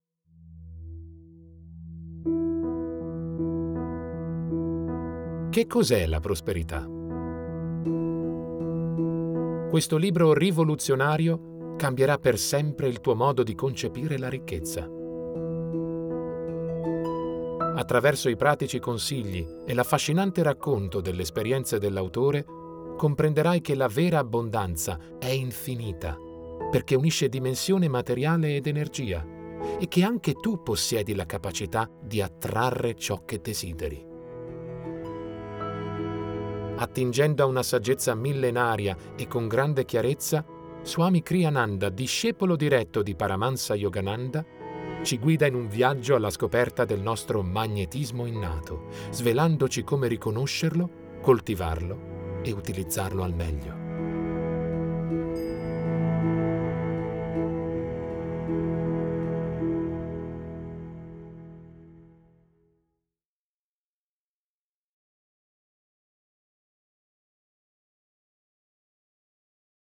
Letto da